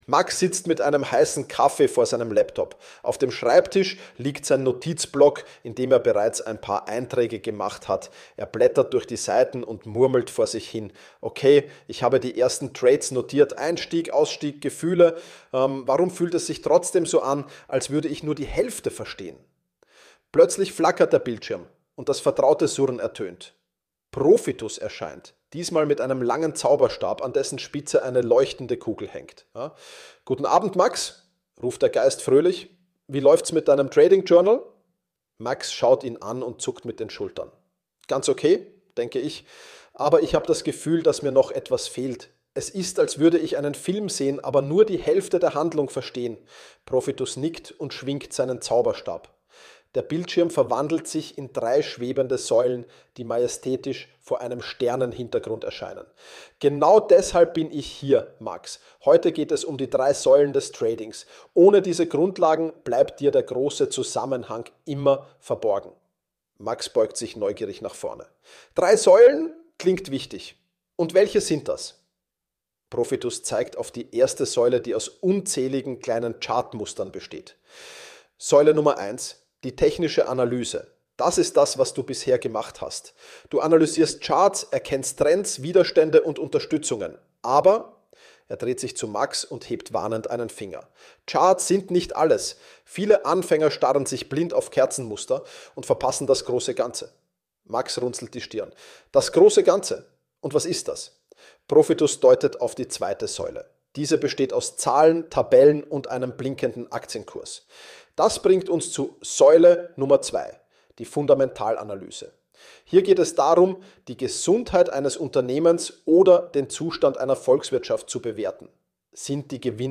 Meine Stimme wurde dafür geklont.